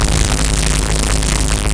ELF_hit.wav